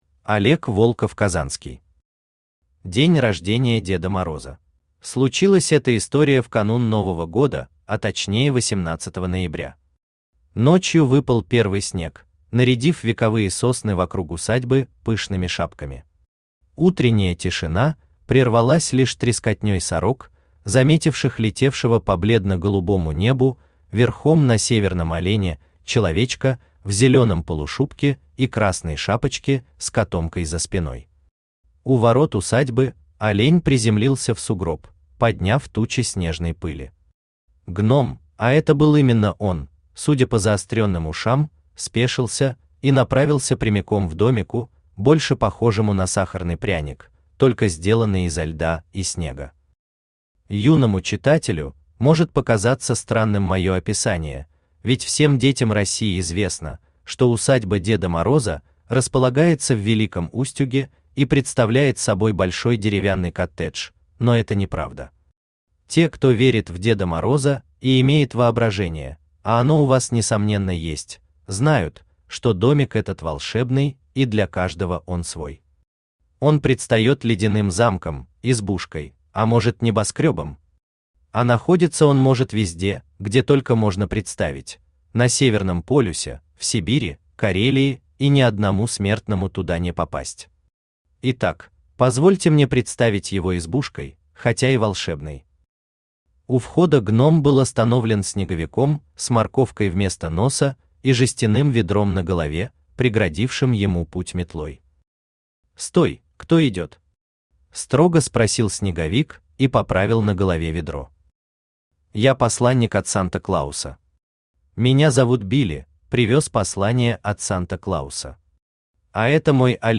Aудиокнига День рождения Деда Мороза Автор Олег Инсанович Волков-Казанский Читает аудиокнигу Авточтец ЛитРес.